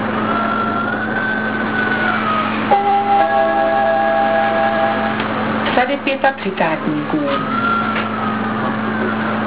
Hlášení zastávek a mimořádností: